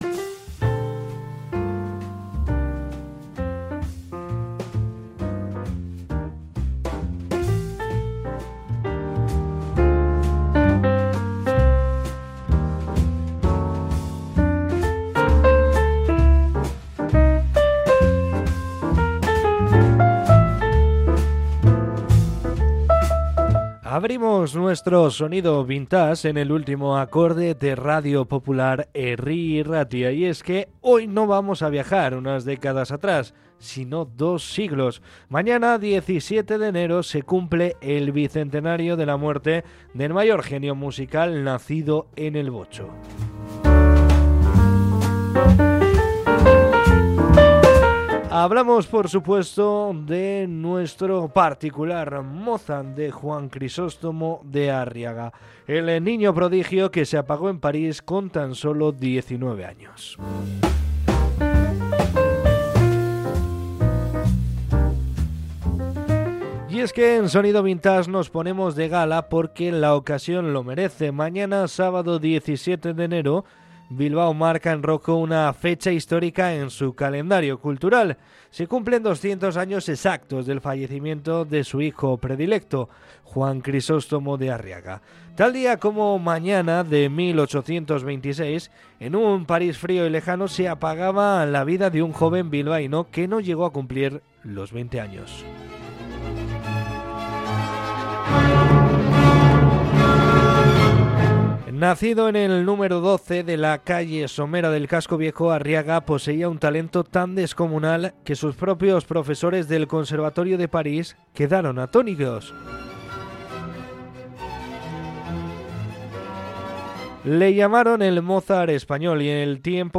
Durante la sección hemos podido escuchar fragmentos de su majestuosa Sinfonía para gran orquesta en Re, una obra que demuestra por qué fue apodado el Mozart bilbaíno.